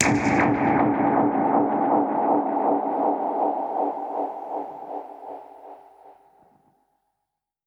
Index of /musicradar/dub-percussion-samples/125bpm
DPFX_PercHit_A_125-03.wav